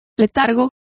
Complete with pronunciation of the translation of lethargy.